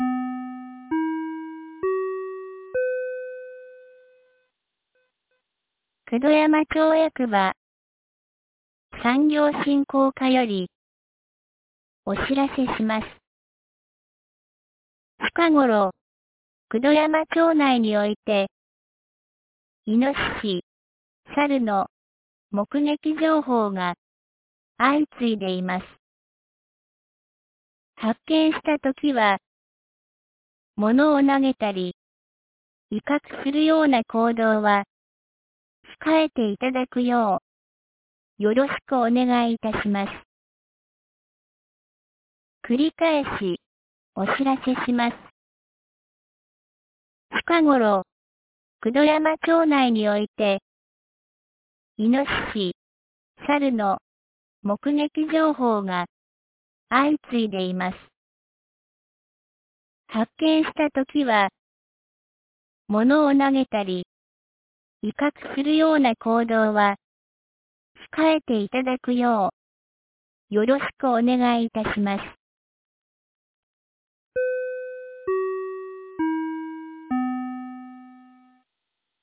2026年04月09日 13時01分に、九度山町より全地区へ放送がありました。